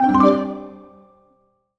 happy_collect_item_03.wav